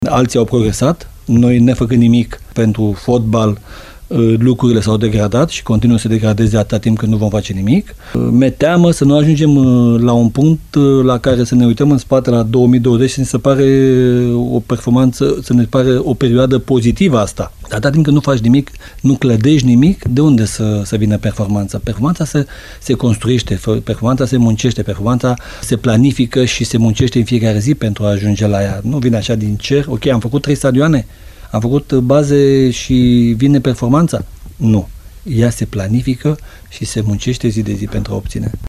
Interviul cu Gheorghe Popescu îl puteți asculta mâine, la emisiunea Arena Radio, pe care Radio Timișoara o difuzează după știrile orei 11,00.